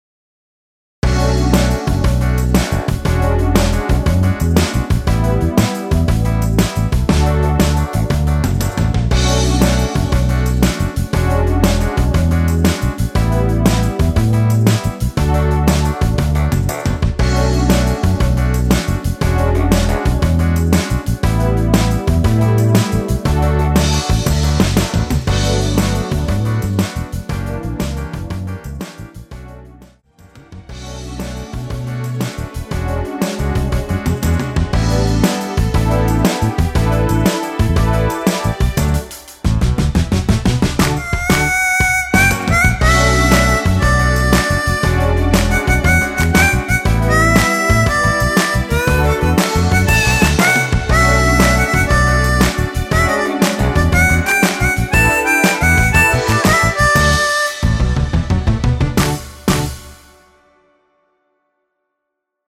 엔딩이 페이드 아웃이고 너무 길어서 노래 하시기 좋게 8마디로 편곡 하였습니다.(미리듣기 참조)
Bm
◈ 곡명 옆 (-1)은 반음 내림, (+1)은 반음 올림 입니다.
앞부분30초, 뒷부분30초씩 편집해서 올려 드리고 있습니다.
중간에 음이 끈어지고 다시 나오는 이유는